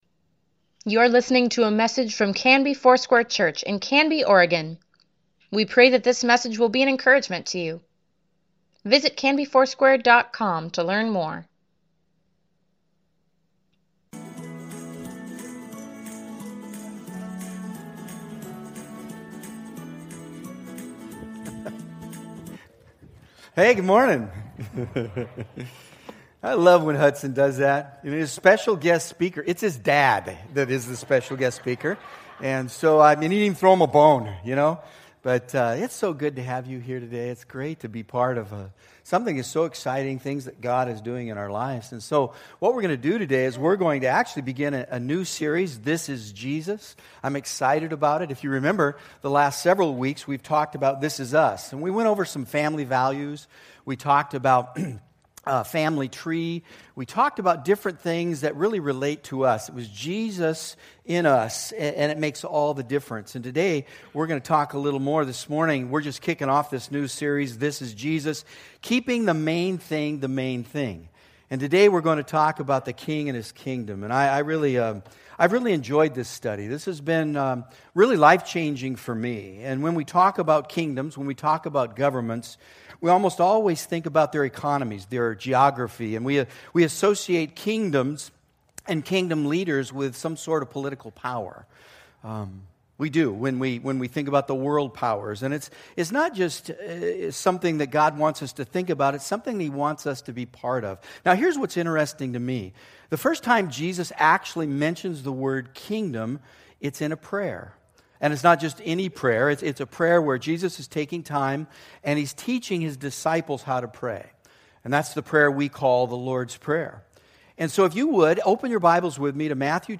Weekly Email Water Baptism Prayer Events Sermons Give Care for Carus This is Jesus: The King and His Kingdom October 22, 2017